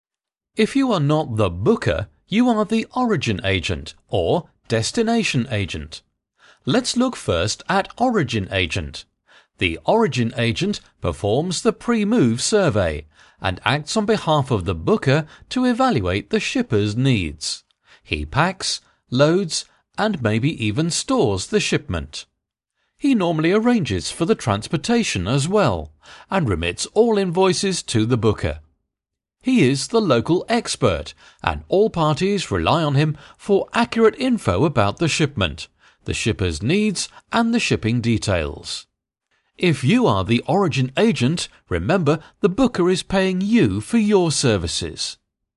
Sprecher englisch (uk).
Sprechprobe: eLearning (Muttersprache):
Voice over talent English (British)(native Speaker).